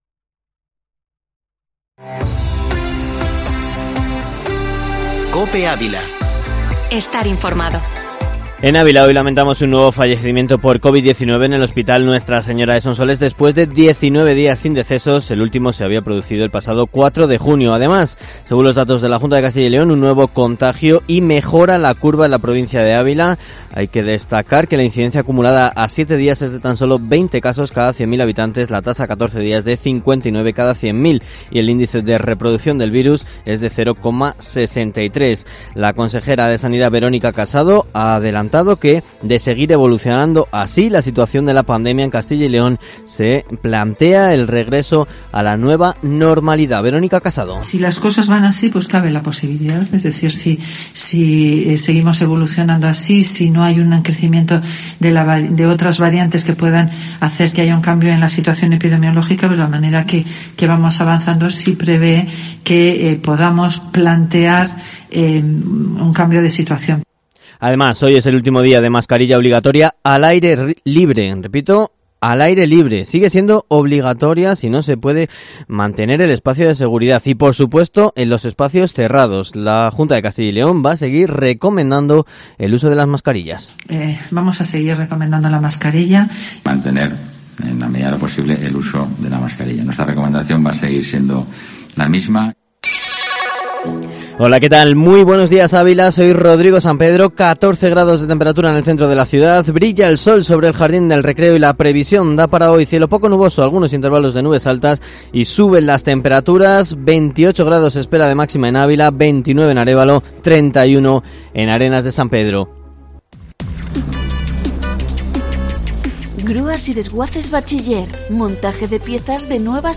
AUDIO: Informativo Matinal Herrera en COPE Ávila, información local y provincial